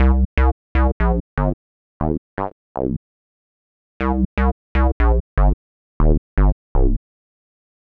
Ew Bass 2.wav